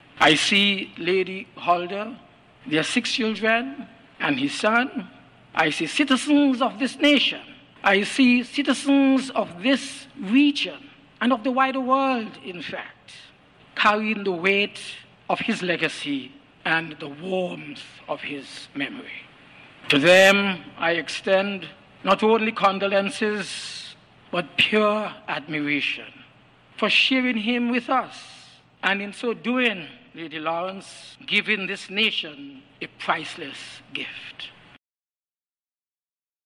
The funeral service took place at the Wesley Methodist Church in Basseterre.
Among them was Senior Minister, the Right Hon. Dr Denzil Douglas: